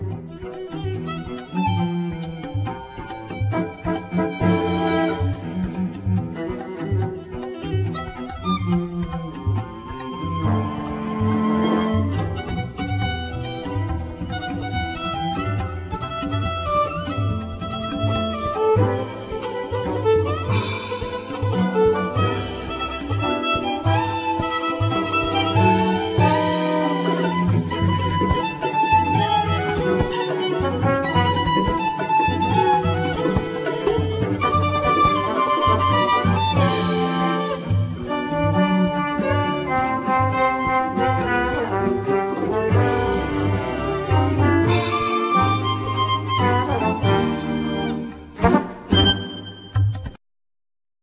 Percussions
Tuba
Violin
Viola
Cello